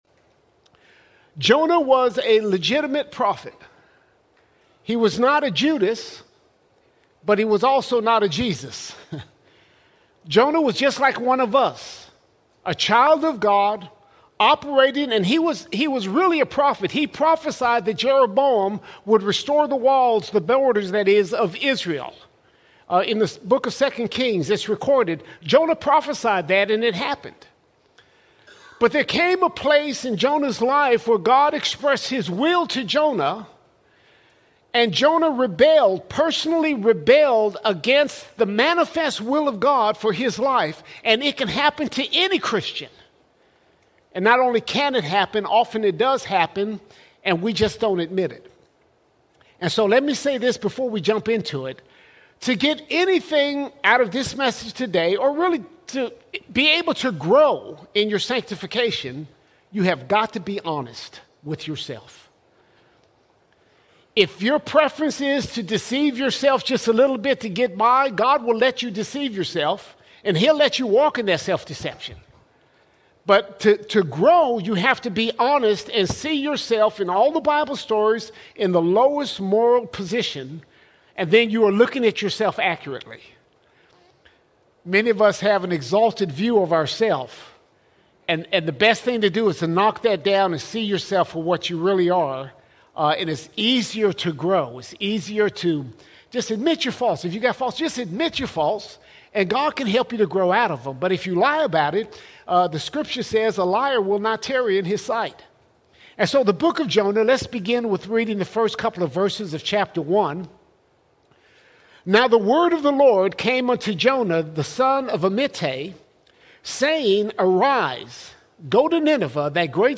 15 September 2025 Series: Sunday Sermons All Sermons Personal Rebellion Personal Rebellion The story of Jonah is a story that reveals the dangers of personal rebellion against God’s call.